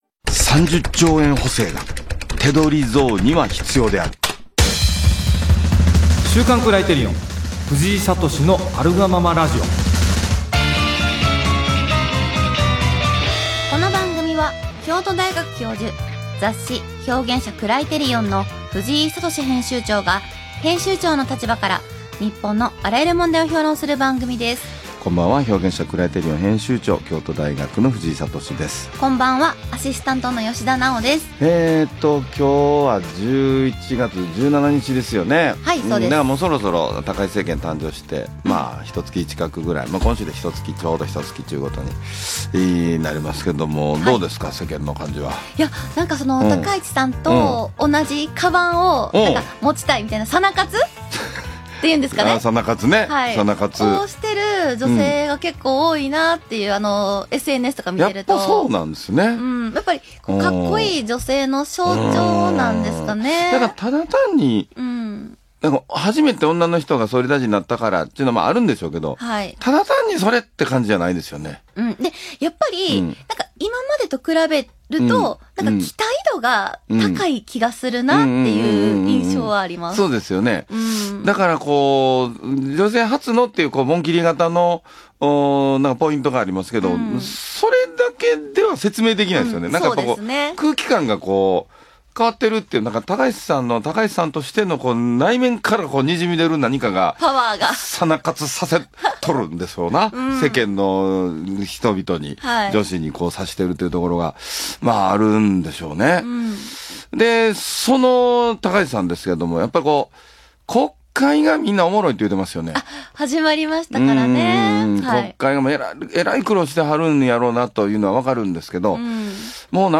【ラジオ】「30兆円」補正が「手取り増」には必要である